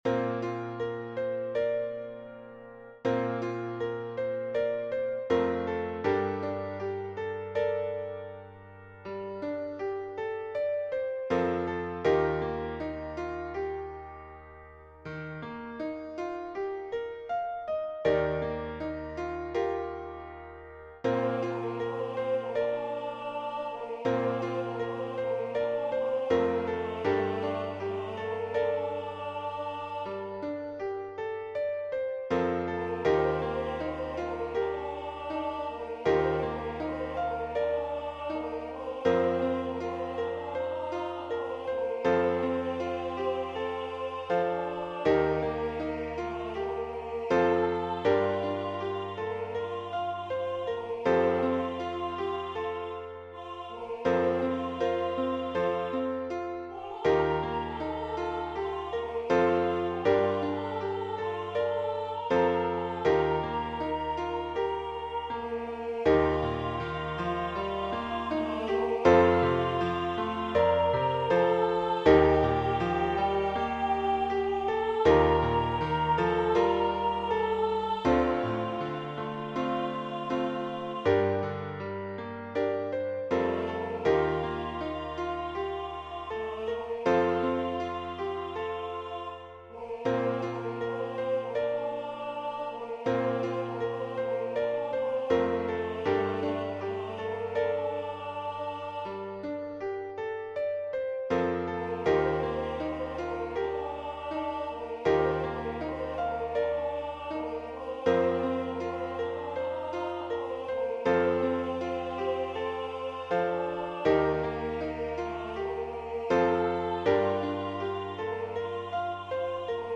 Voicing/Instrumentation: Vocal Solo